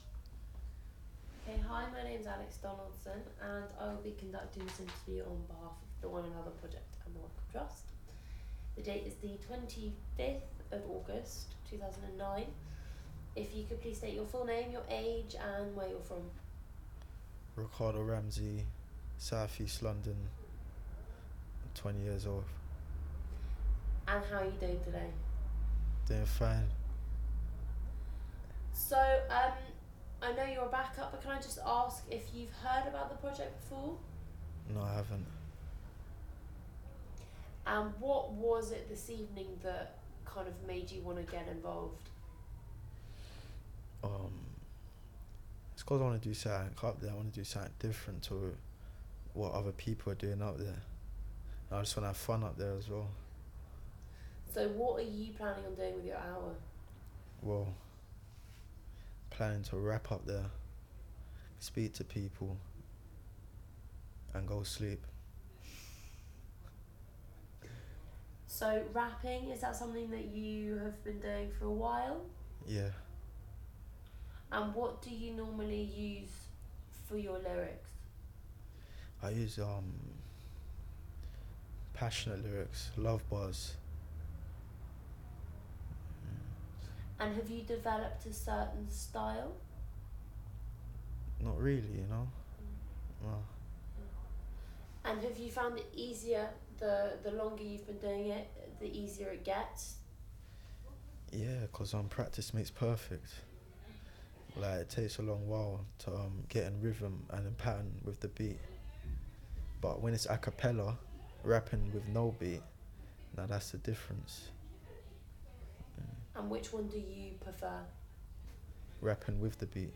Audio file duration: 00:09:49 Format of original recording: wav 44.1 khz 16 bit ZOOM digital recorder.